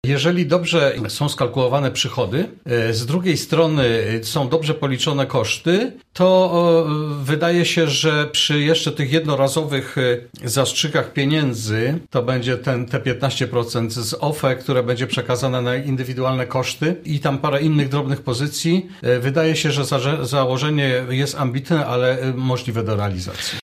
Stanisław Iwan, który był gościem Radia Zachód mówi, że plan jest ambitny, ale możliwy do zrealizowania.